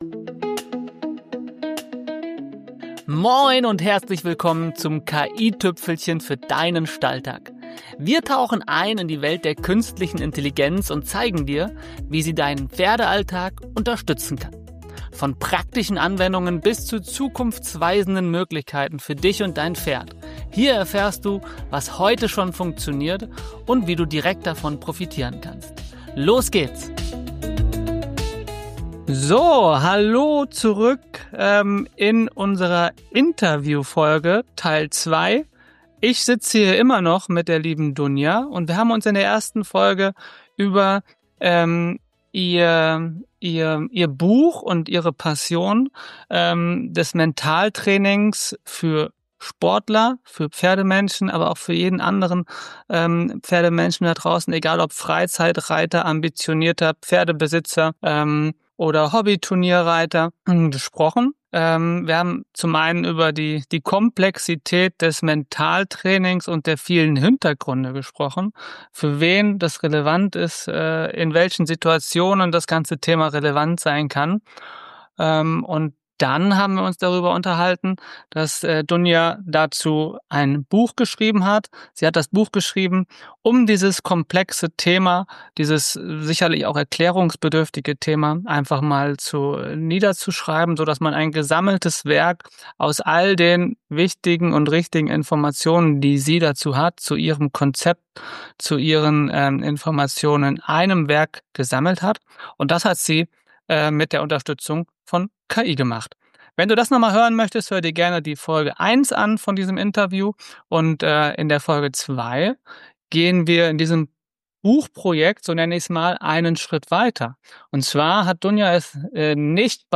Ein inspirierendes Gespräch über Fokus, Klarheit und die Verbindung von Mentaltraining, Reitsport und künstlicher Intelligenz.